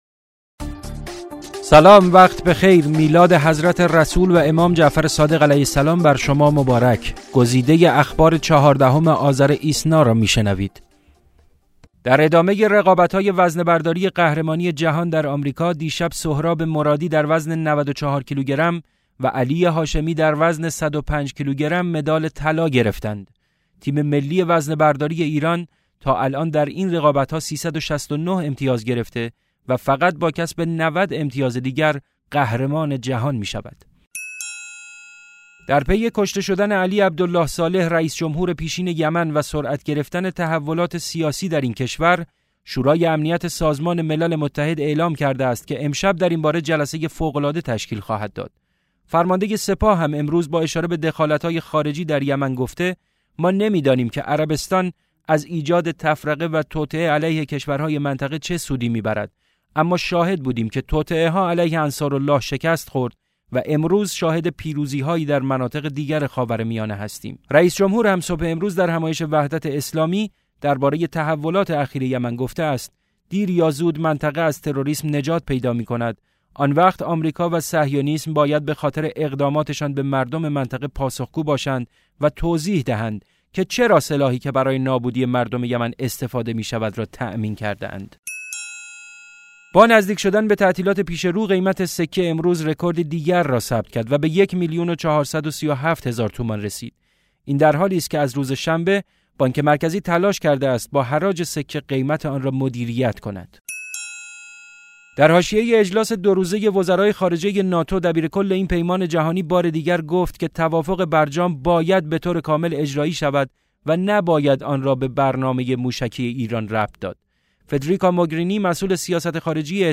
صوت / بسته خبری ۱۴ آذر ۹۶